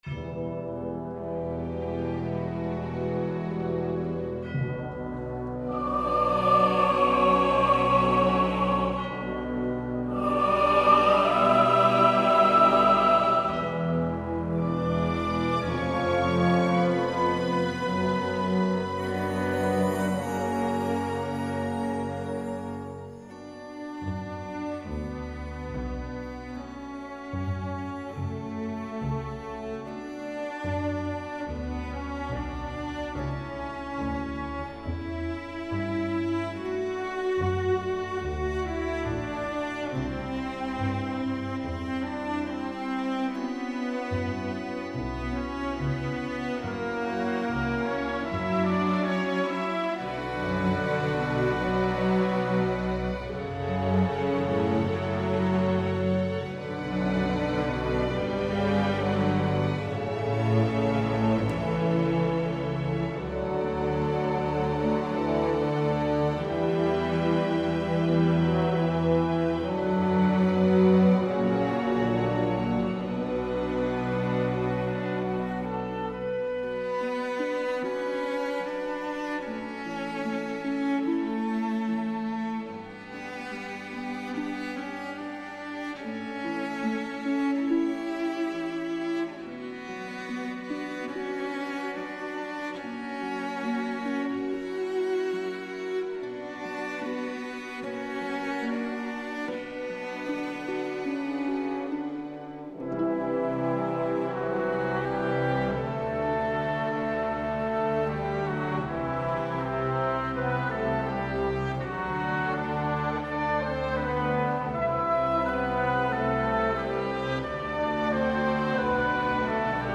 Ein Song aus dem beeindruckenden Soundtrack des Spiels.